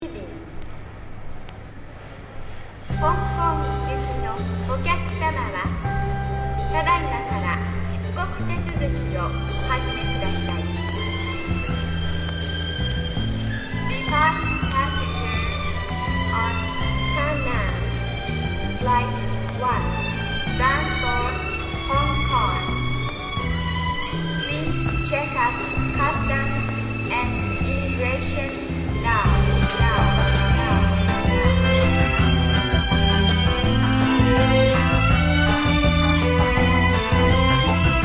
空港ラウンジのアナウンスから始まるA-1はじめ、心地よい メロディが本当に気持ちい良くて全曲オススメです!!
Tag       FREE SOUL OTHER